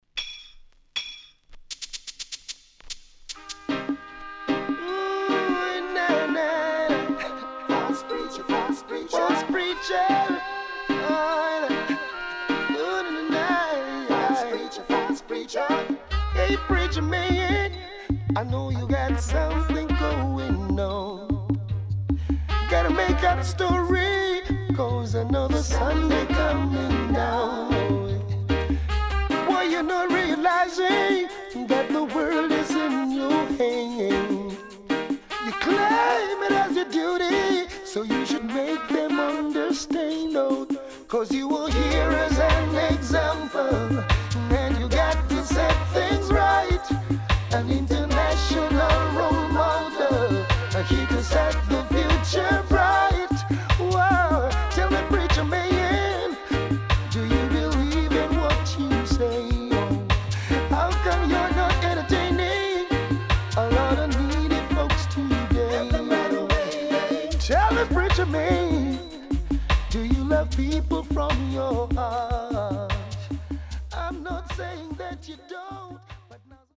渋Vocal